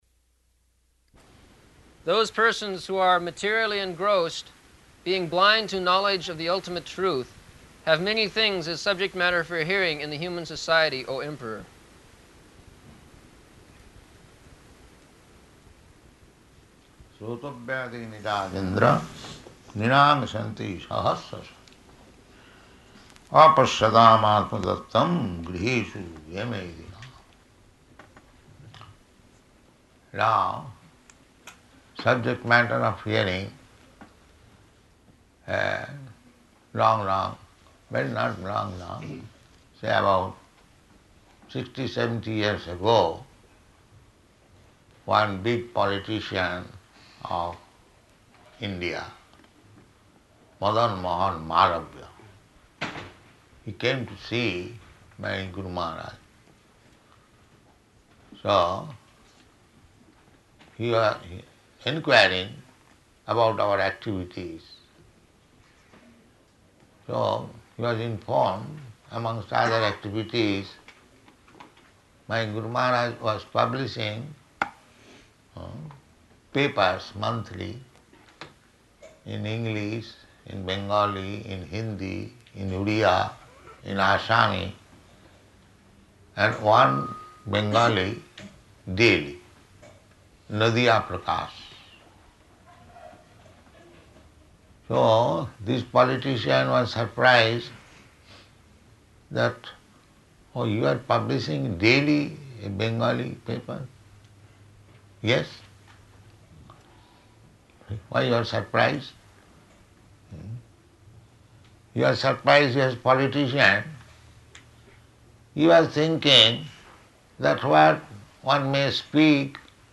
Location: Paris